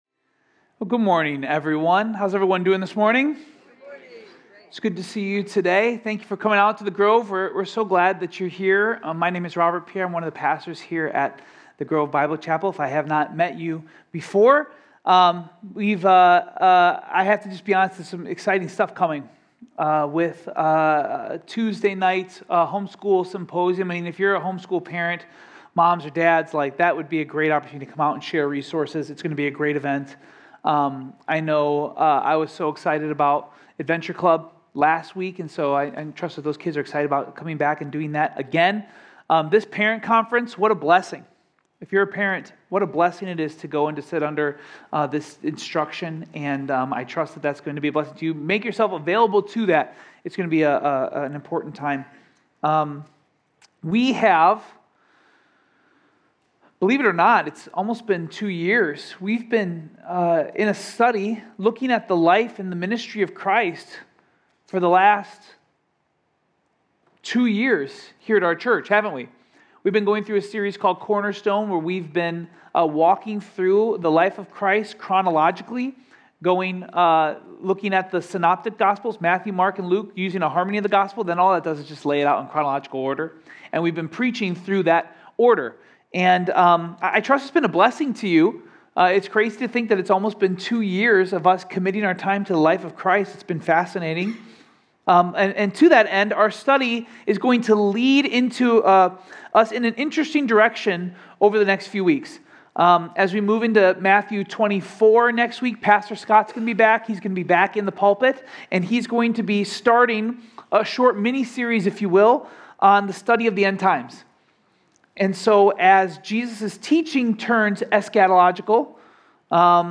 Home Sermons Cornerstone